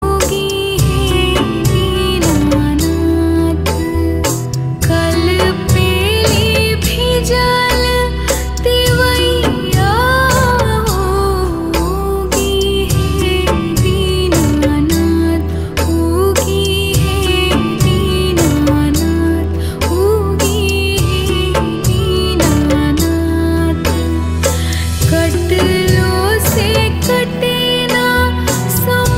Bhojpuri Songs
• Simple and Lofi sound